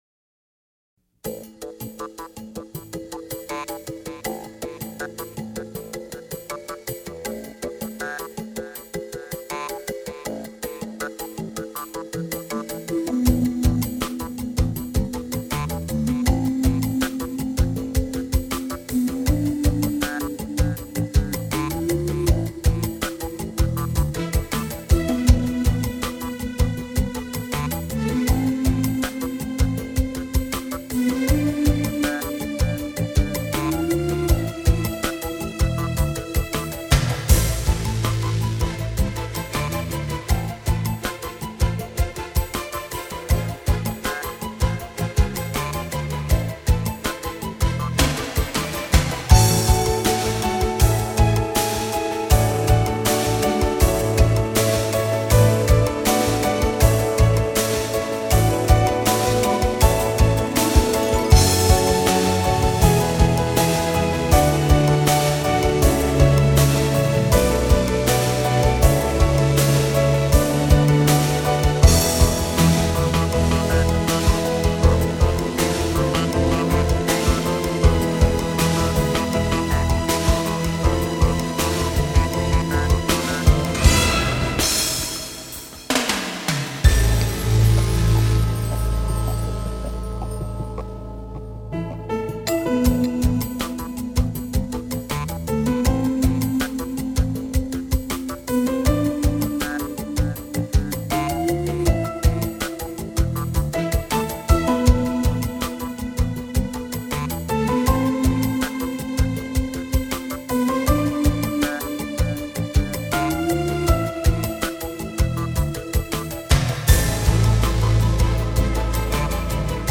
而这碟音乐虽然音乐大部分是动感而喧哗的，但是，那种活力可以完全感染我们！